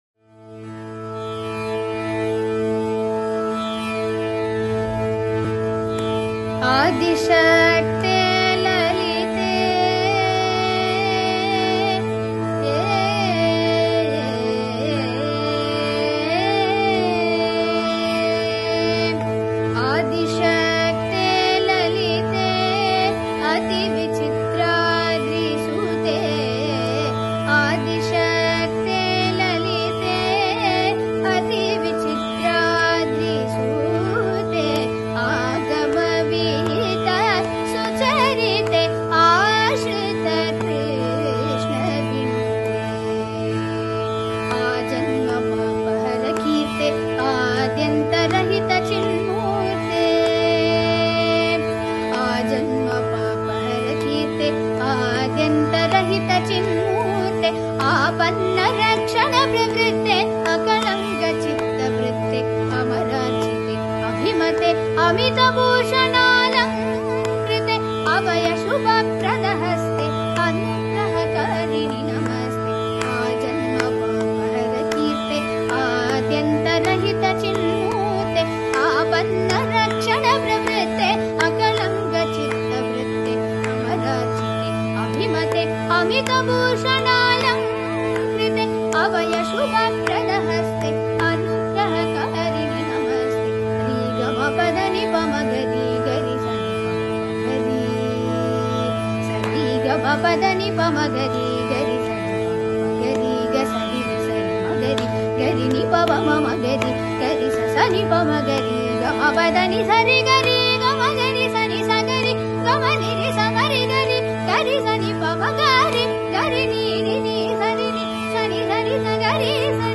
rudrapriya
khanda chAppu